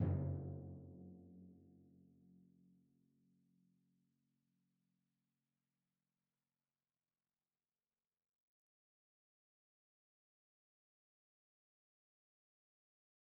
Timpani1_Hit_v3_rr4_Sum.mp3